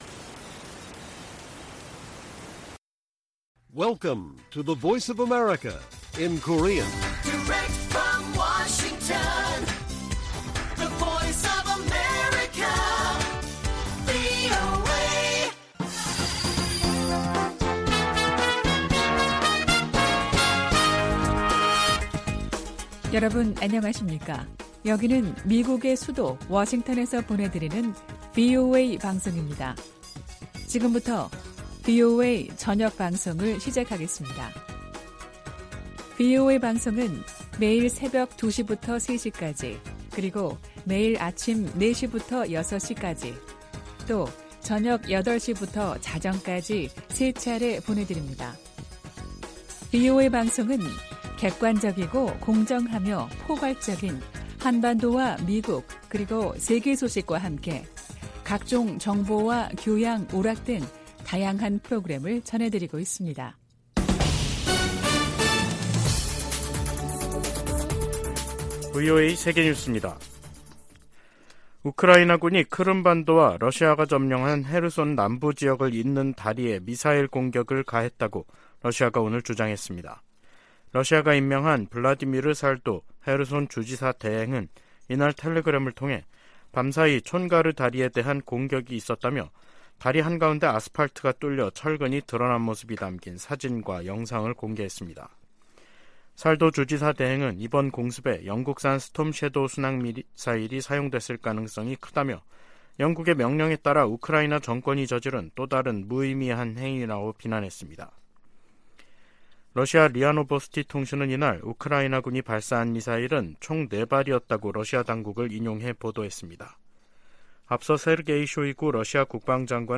VOA 한국어 간판 뉴스 프로그램 '뉴스 투데이', 2023년 6월 22일 1부 방송입니다. 미 국무부는 중국이 북한 문제를 해결할 역량과 책임이 있다는 점을 거듭 강조했습니다. 미국은 자산 동결 등 조치를 통해 북한이 탈취 암호화폐를 미사일 프로그램에 사용하는 것을 막았다고 법무부 고위 관리가 밝혔습니다. 주한미군 고고도 미사일 방어체계 즉 사드(THAAD) 기지가 인체에 미치는 영향은 미미하다고 한국 정부의 환경영향 평가가 결론 지었습니다.